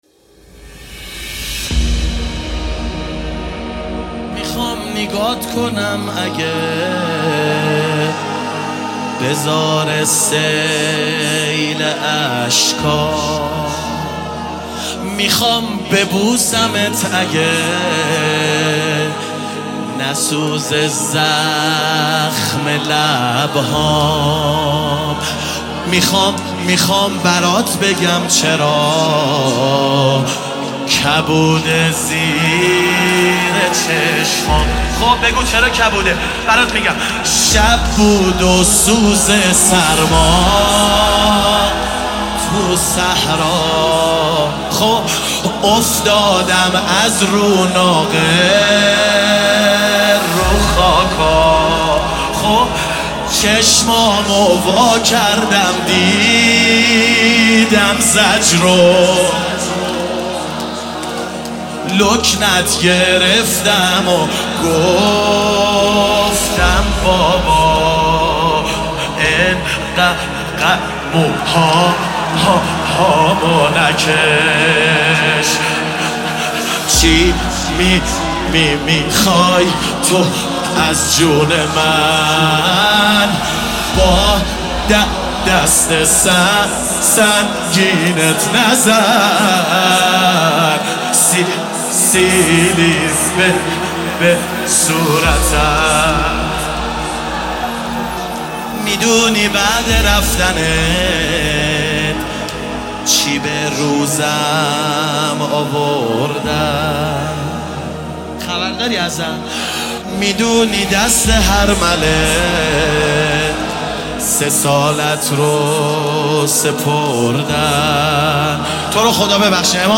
ایام فاطمیه 1442 | هیئت معظم کربلا کرمان